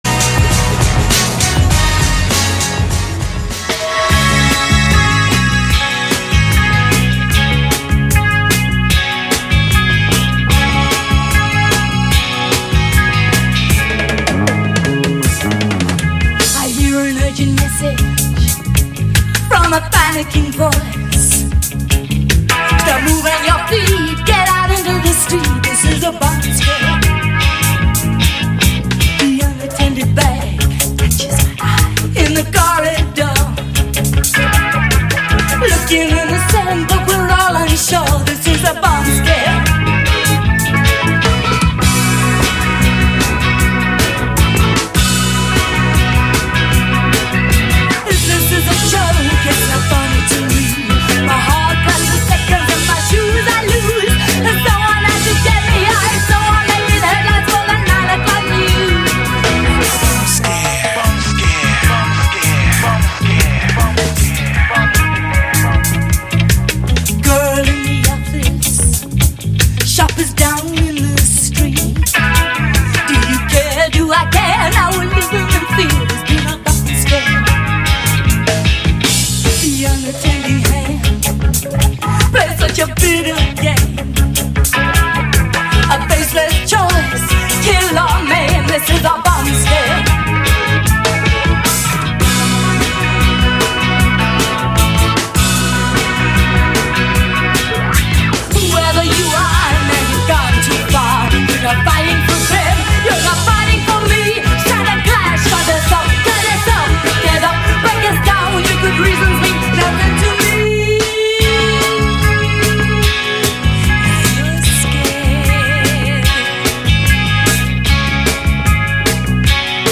Vos esgourdes seront abreuvées de 50’s Rockabilly